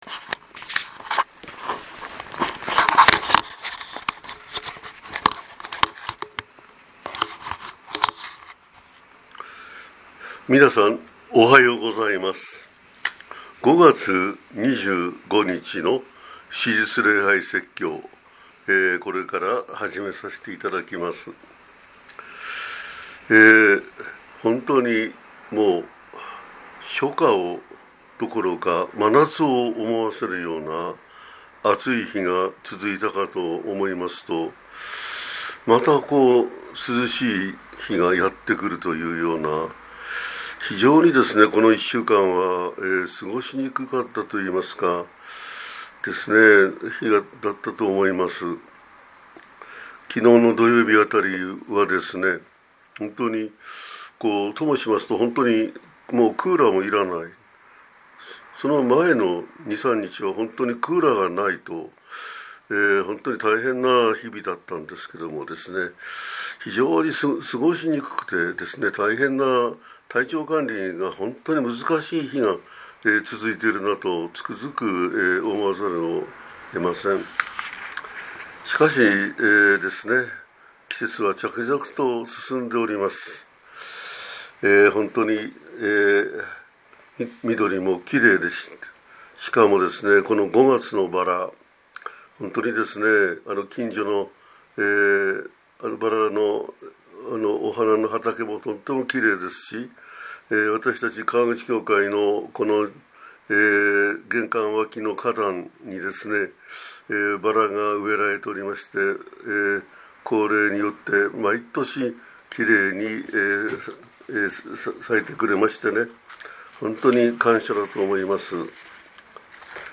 2025年5月25日（復活節第6主日）